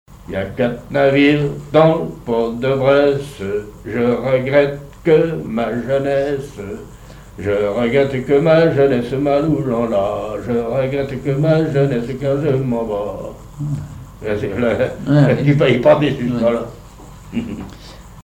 gestuel : à marcher
Genre énumérative
chansons populaires et traditionnelles maritimes
Pièce musicale inédite